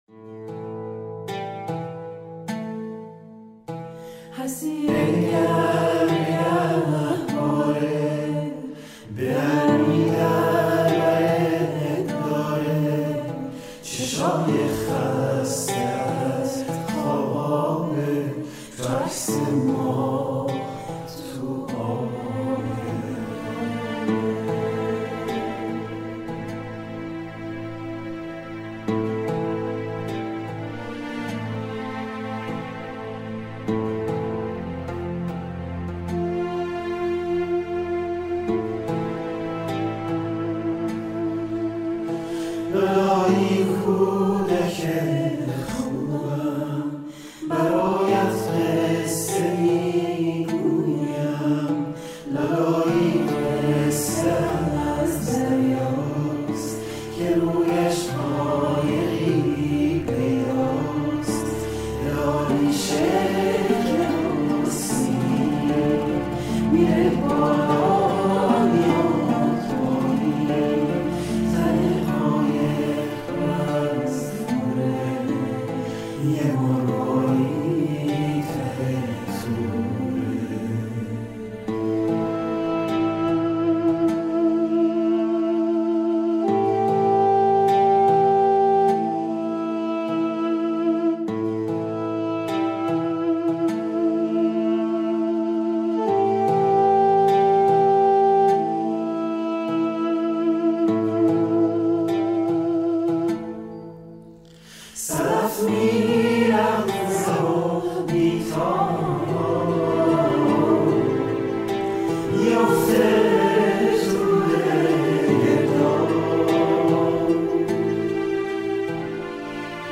آهنگ لالایی